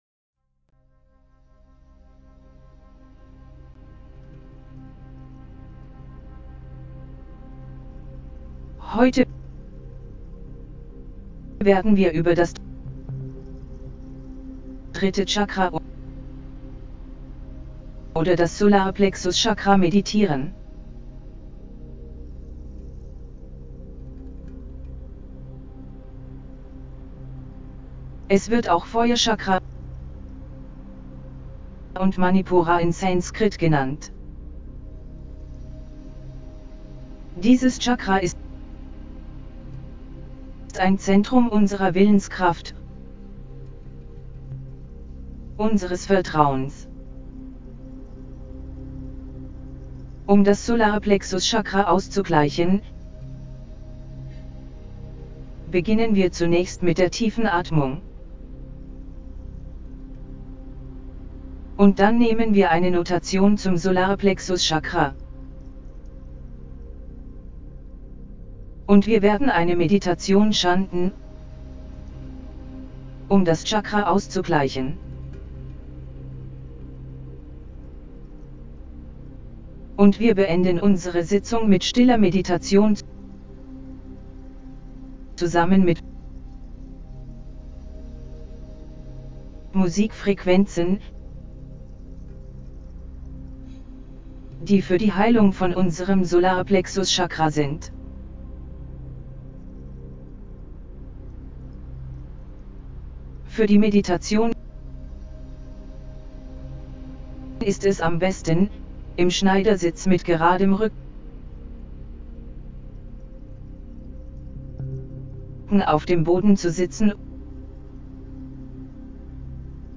3SolarPlexusChakraHealingGuidedMeditationDE.mp3